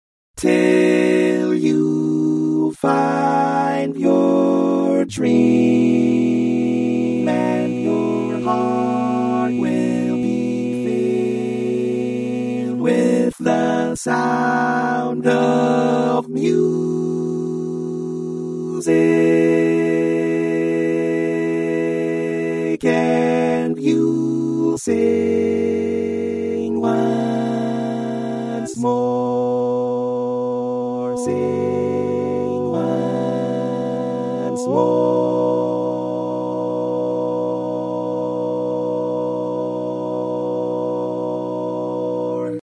Key written in: B Major
Type: Barbershop